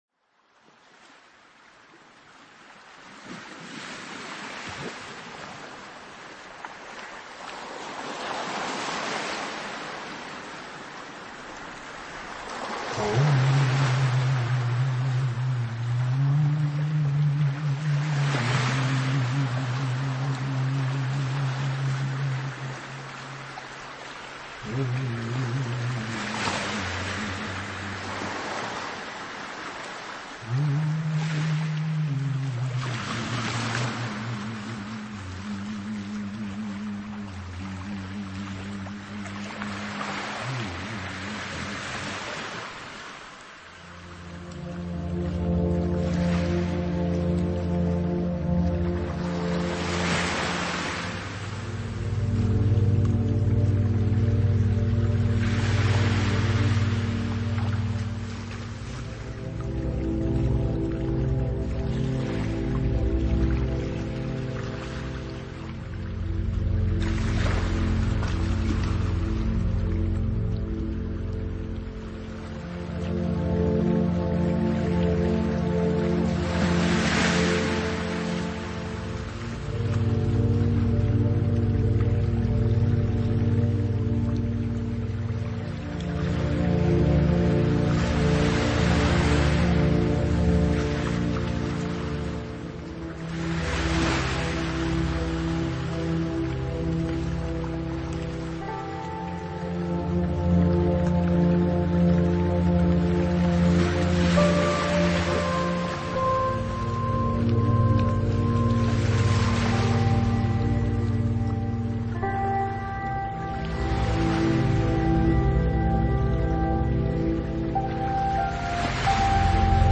nature sounds and atmospheric sounds, perfect for relaxation
new age
ambient